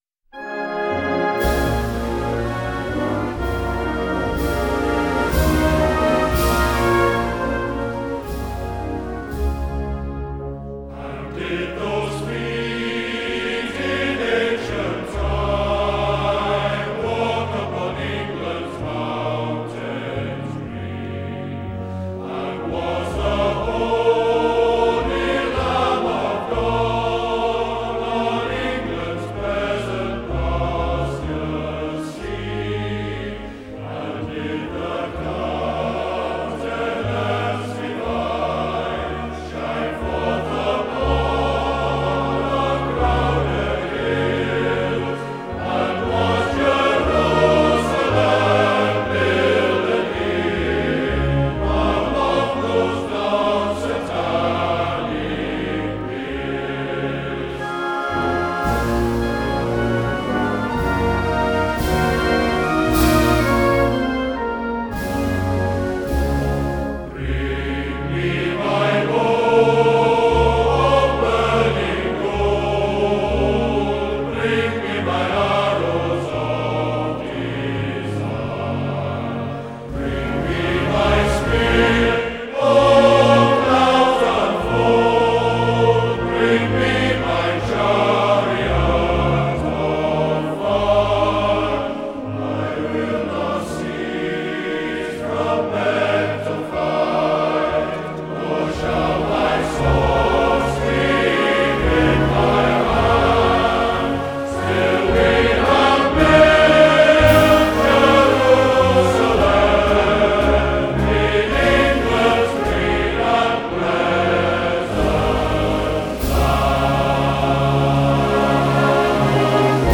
I couldn’t find the exact versions of the Hymns from the funeral so I have some different renditions by military bands, which are just as nice. I have to say the Honley Male Voice Choir sing Jerusalem much better than we did.